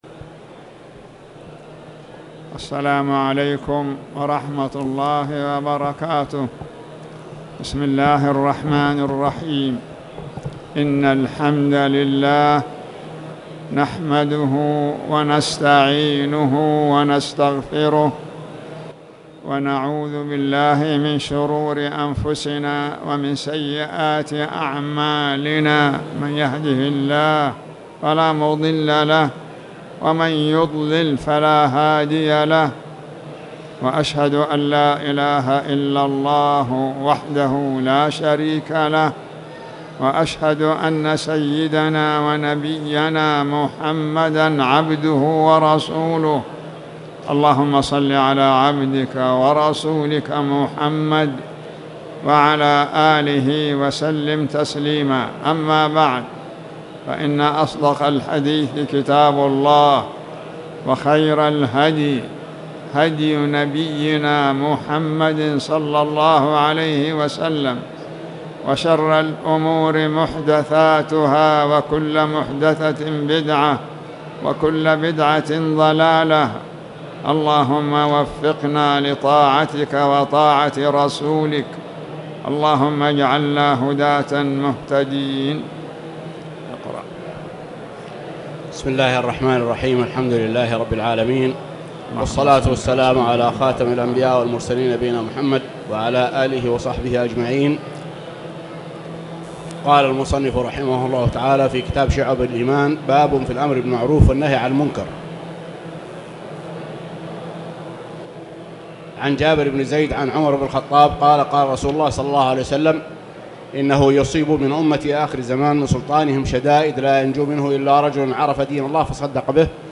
تاريخ النشر ١٩ رجب ١٤٣٨ هـ المكان: المسجد الحرام الشيخ